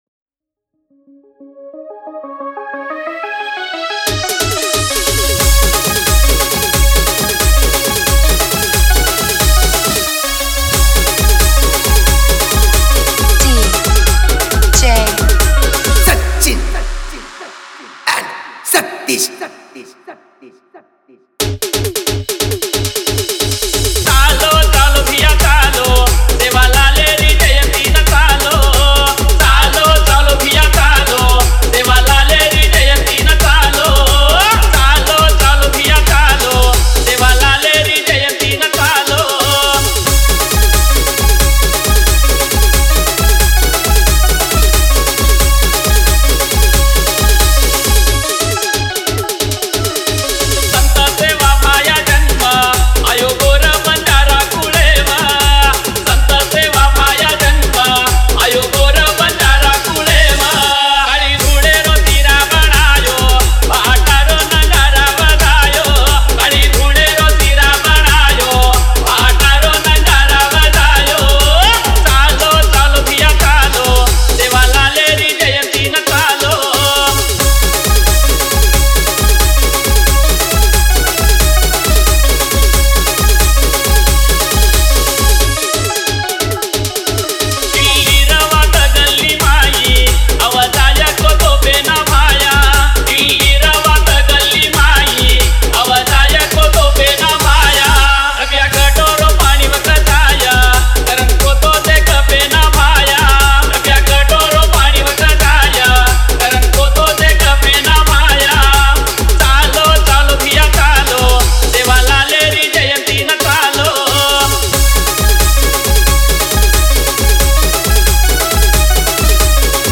Banjara Dj Tracks